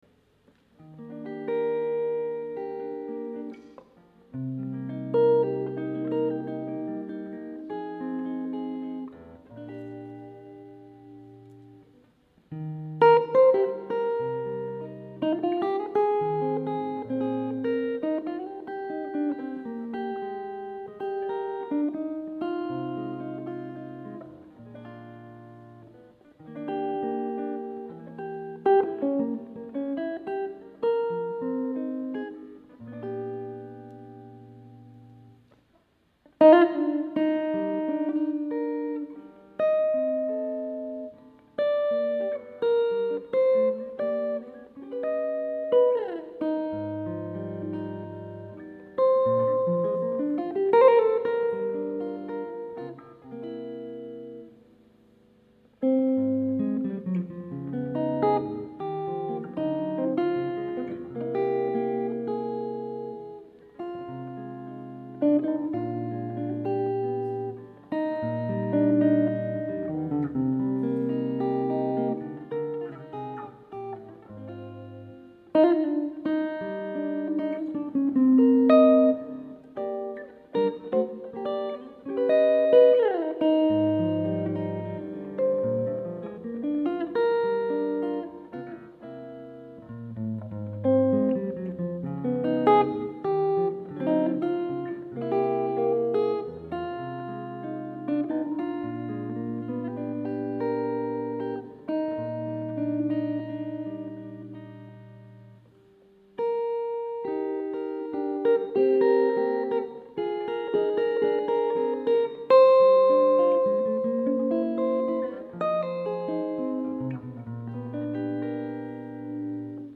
recent graduate recital
This is a live recording of his arrangement and performance of the classic Over the Rainbow in a medley with a tune by Malaysian composer P. Ramlee called Getaran Jiwa.